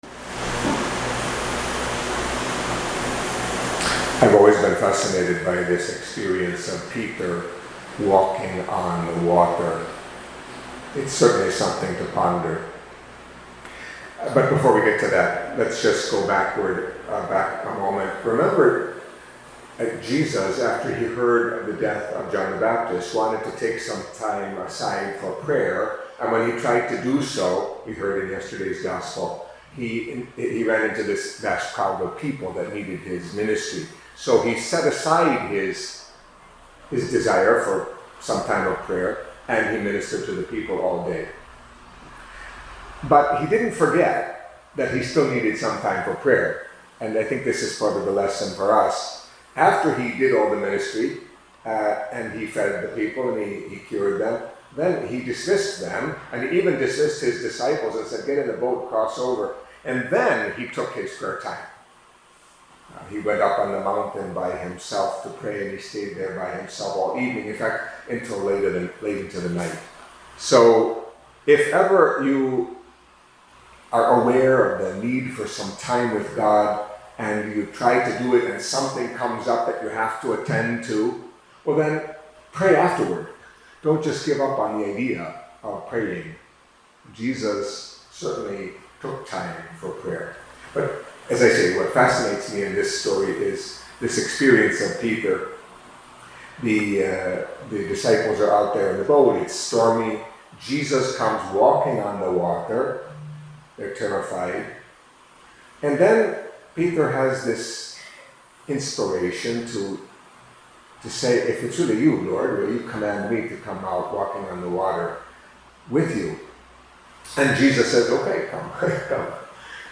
Catholic Mass homily for Tuesday of the Eighteenth Week in Ordinary Time